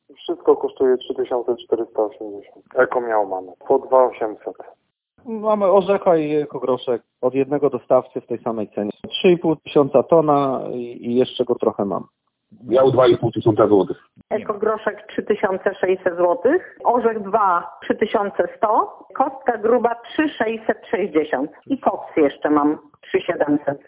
Jakie są ceny opału w zachodniopomorskich składach, o tym mówią ich właściciele.
SZCZ-Sonda-Wegiel.mp3